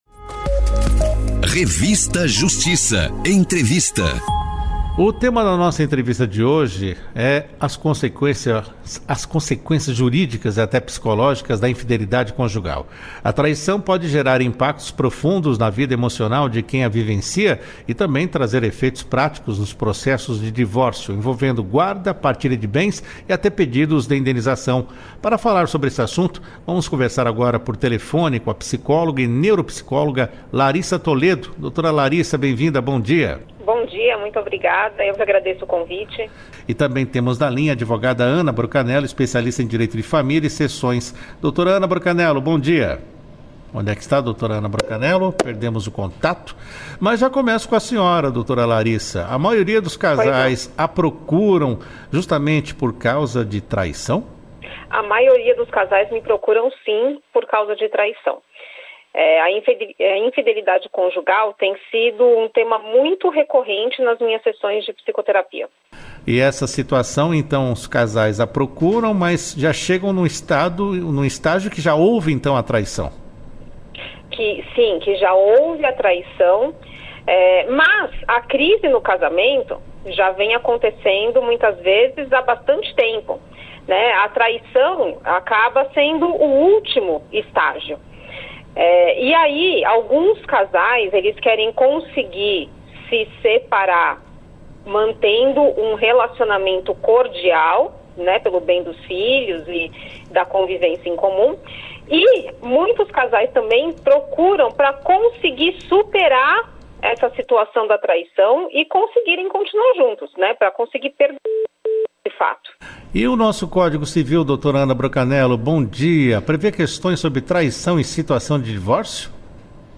Foi um bate-papo profundo, com reflexões que vão além do jurídico, para ajudar quem está passando por esse momento a tomar decisões mais conscientes.